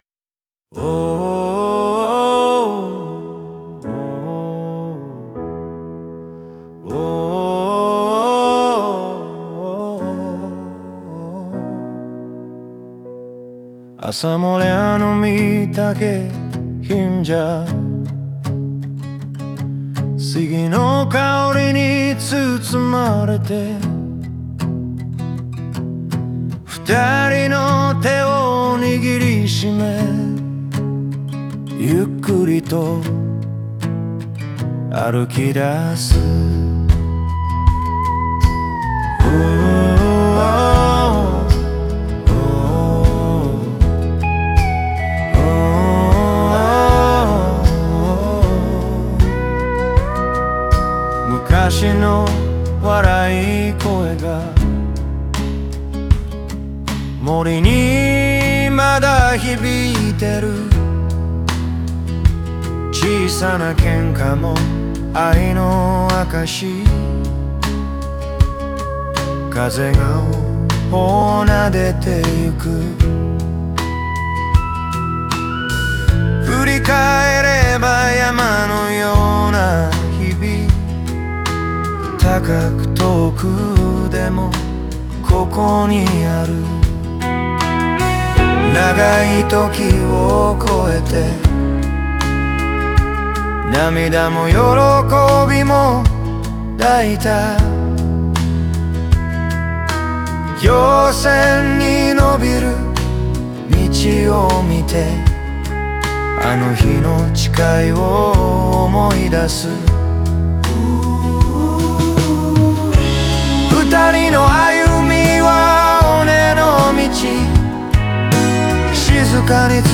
音楽はアコースティック中心で、静かなバックコーラスが回想のように包み込み、聴く者に温かな余韻を残す。